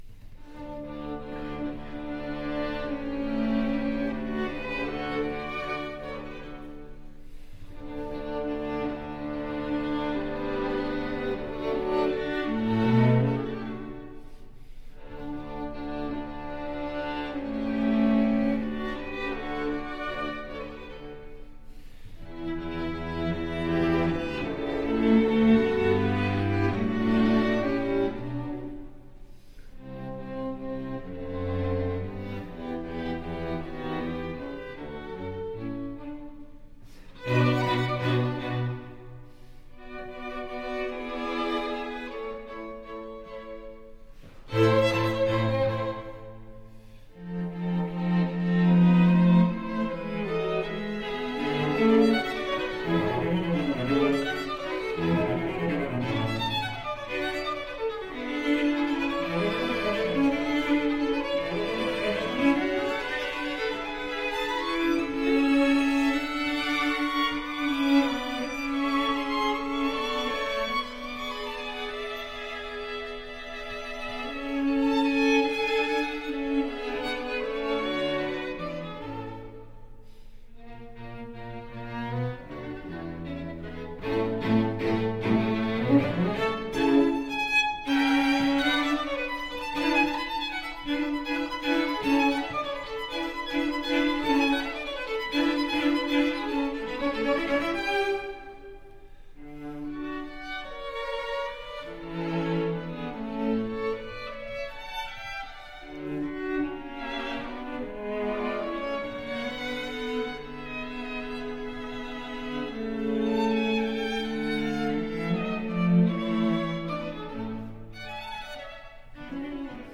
Violin 1 Violin 2 Viola Cello
Instrument: String Quartet
Style: Classical
Audio: Boston - Isabella Stewart Gardner Museum
Audio: Jupiter String Quartet